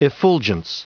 Prononciation du mot effulgence en anglais (fichier audio)
Prononciation du mot : effulgence